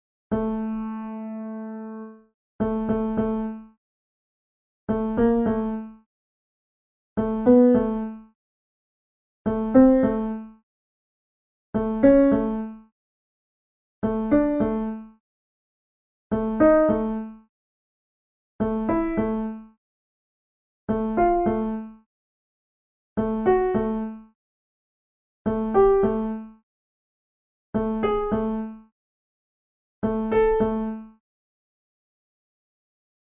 Chromatic Scale
chromatic-scale-online-program